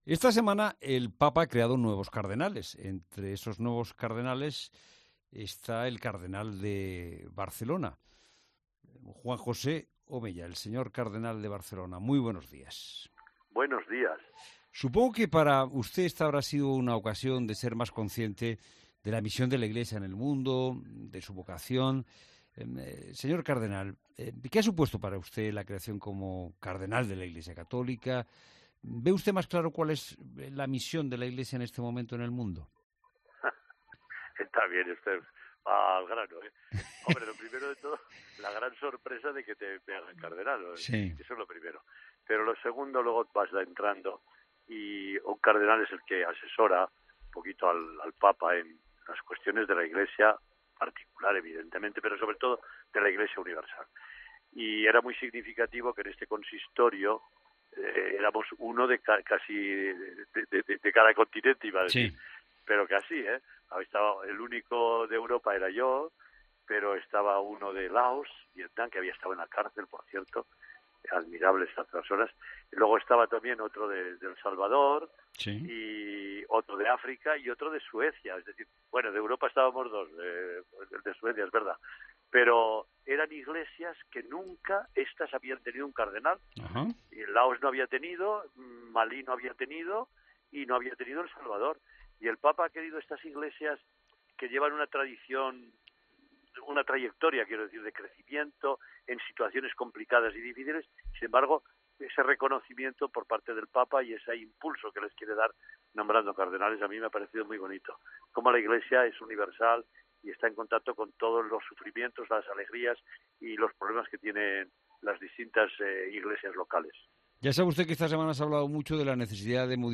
Entrevista política